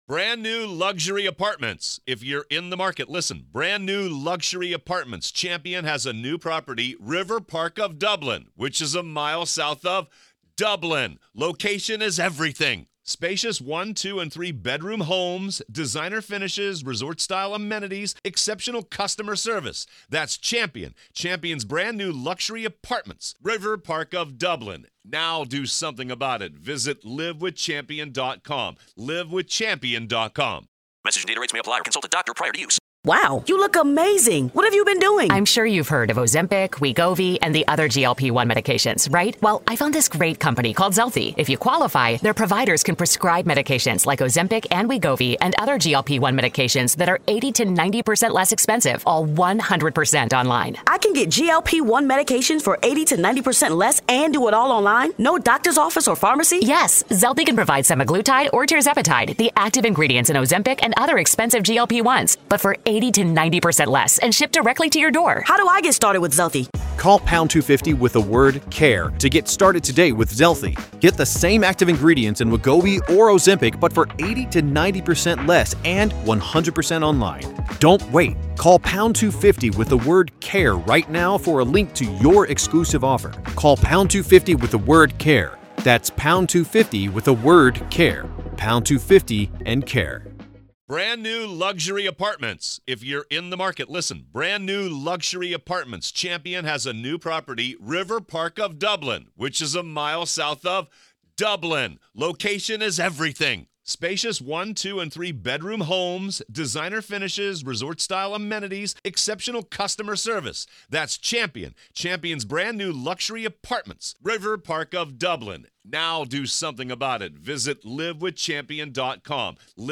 The discussion delves into the tactical approaches of the defense, potential overstatements, and the broader implications of community connections in small-town legal proceedings. Main Points of the Conversation: - **Complexity and Unexpected Turns in the Trial**: The case initially seemed straightforward but has evolved with more questions than answers, challenging previous assumptions about its simplicity.